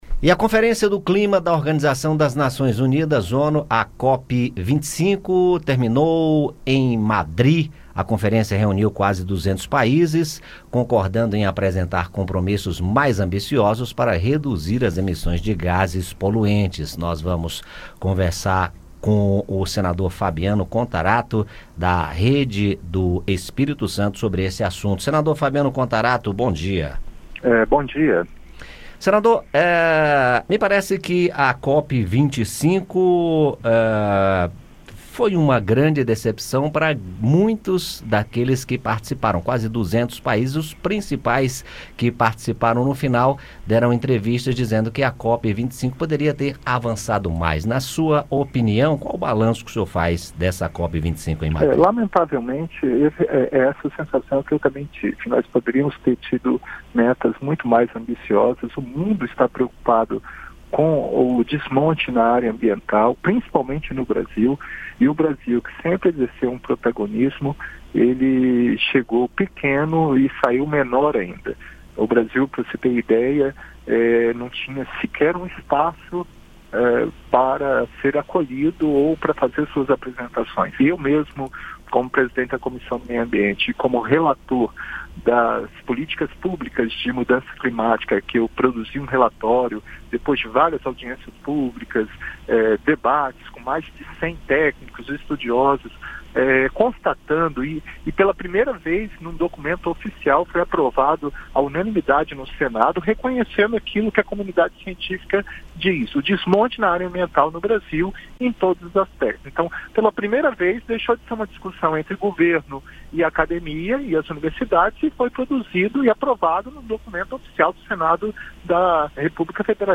Terminou no último domingo, em Madri, na Espanha, a Conferência do Clima da Organização das Nações Unidas (ONU) de 2019, COP-25. E convidamos o senador Fabiano Contarato (Rede-ES), presidente da Comissão de Meio Ambiente (CMA) do Senado, para fazer um balanço do evento. Ouça o áudio com a entrevista.